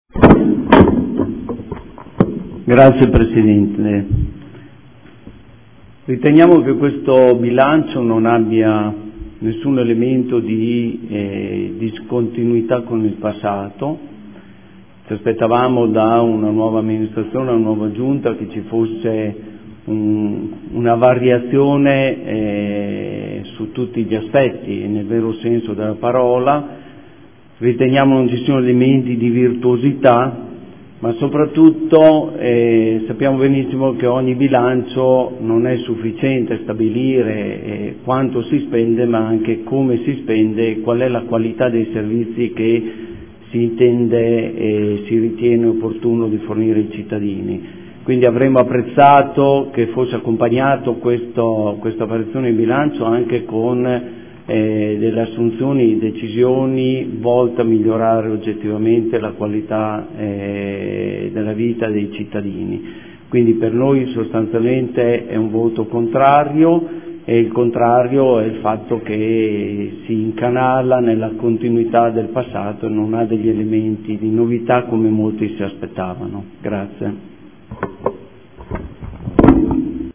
Dichiarazione di voto.
Audio Consiglio Comunale